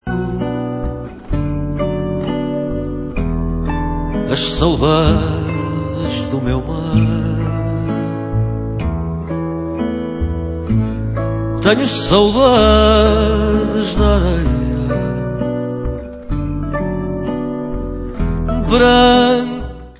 sledovat novinky v oddělení World/Fado